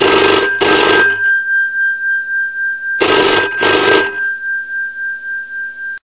Classic-Telephone-Ring